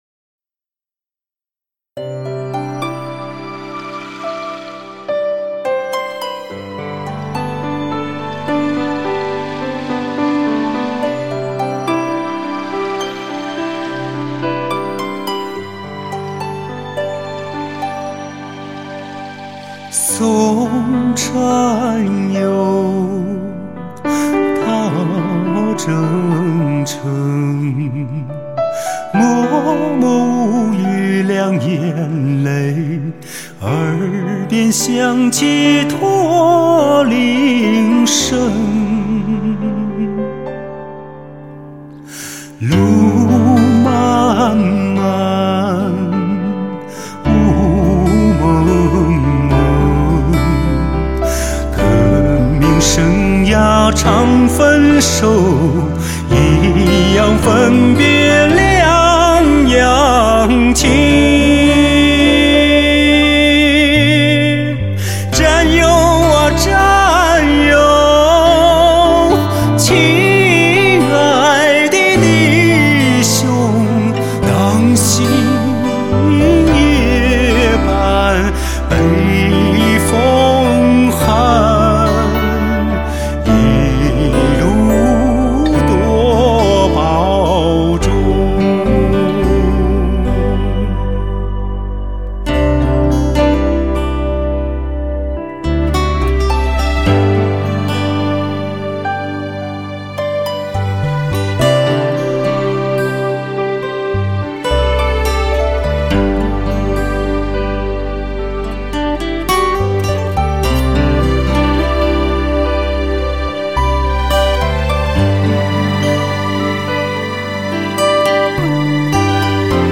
超感性男声柔情与温情的写实演绎 浮躁的午夜 寂寞的天堂
纯美温馨的歌声幽幽响起 你是否听到深情优雅的感性男声